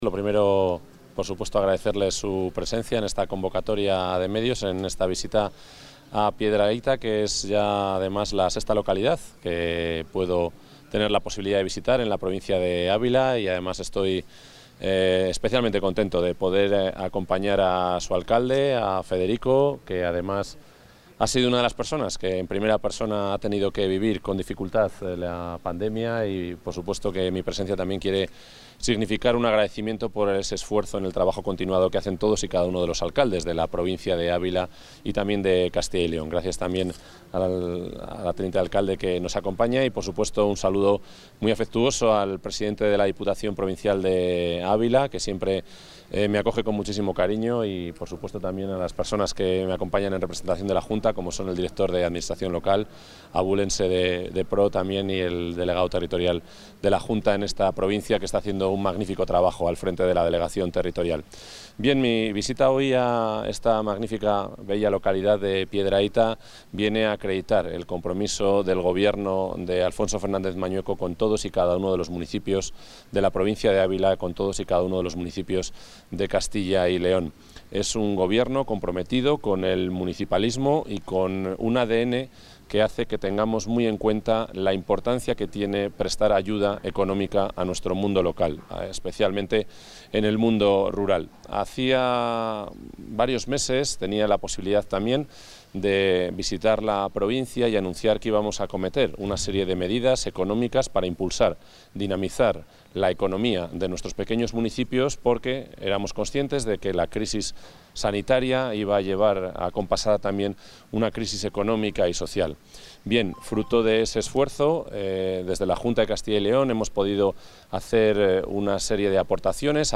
Intervención del consejero de la Presidencia.